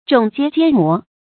踵接肩摩 zhǒng jiē jiān mó
踵接肩摩发音